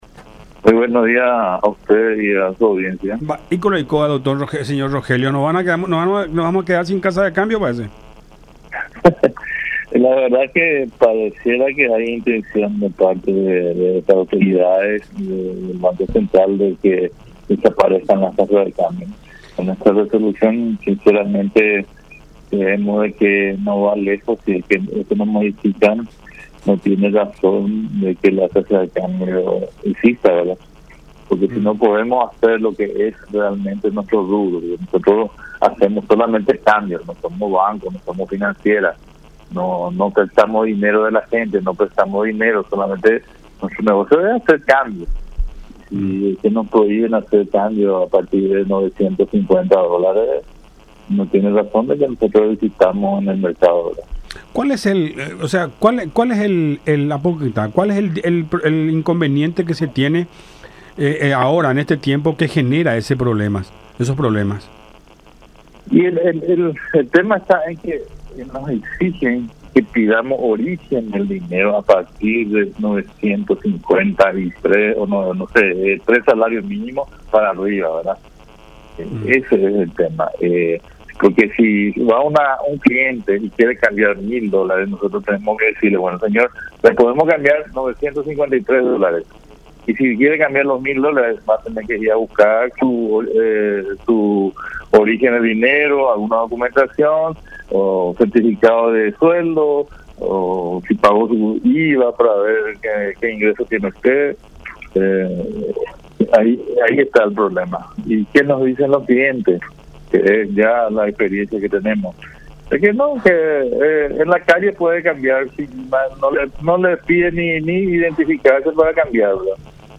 en comunicación con La Unión R800 AM